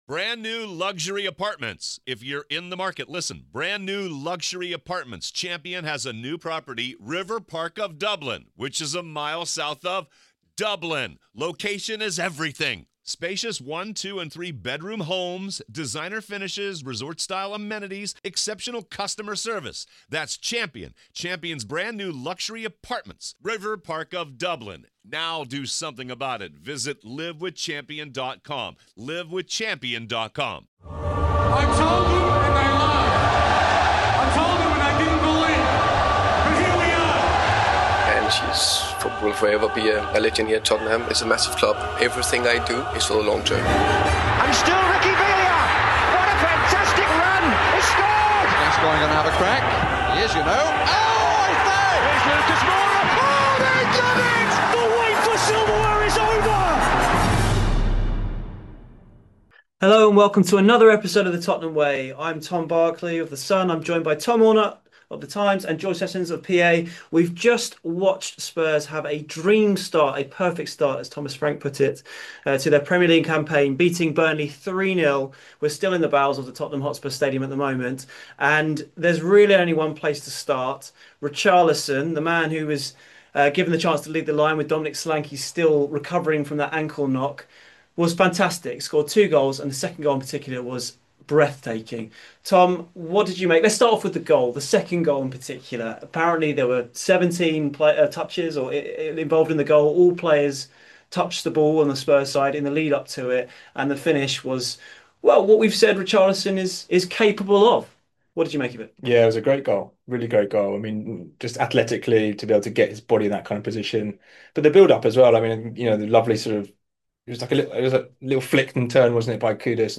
convene at the Tottenham Hotspur Stadium to review an excellent opening-day win over Burnley - with a lot of love for Richarlison.